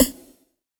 SNARE 05  -R.wav